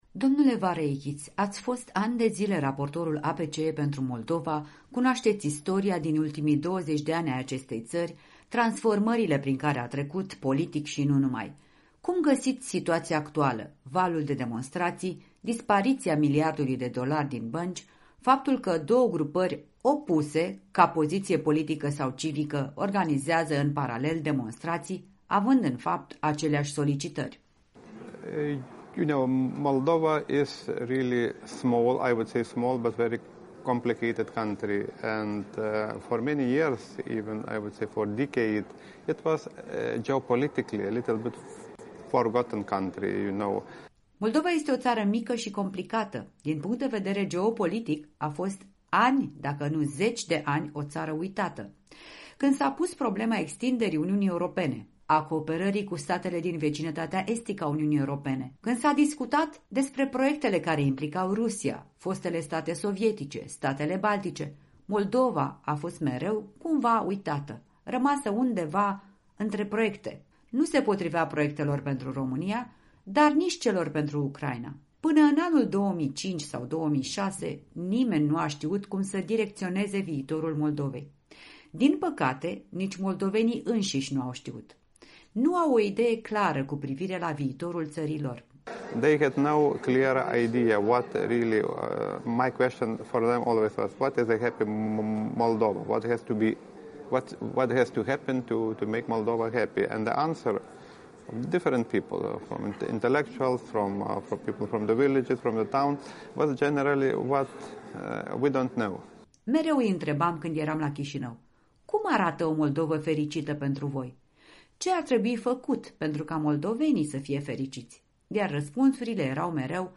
Interviul dimineții: Egidius Vareikis despre sitația politică din Moldova